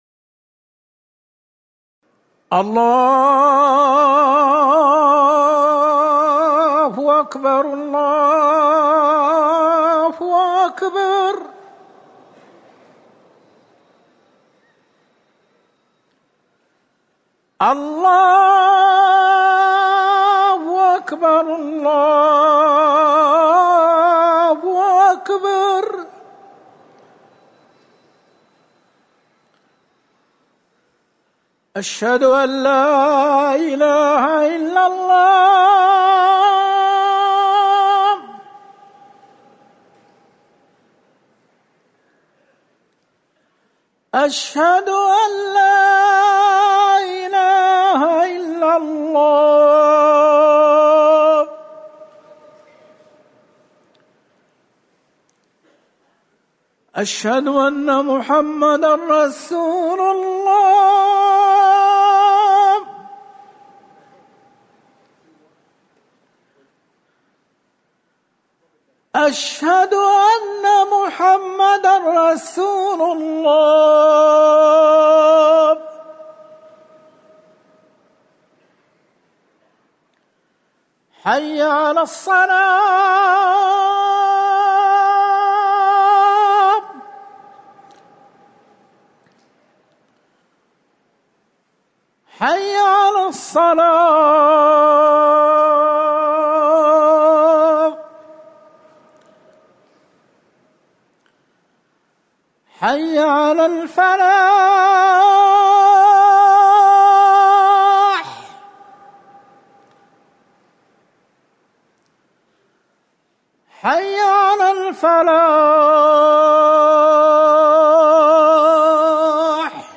أذان العشاء
تاريخ النشر ٢٣ محرم ١٤٤١ هـ المكان: المسجد النبوي الشيخ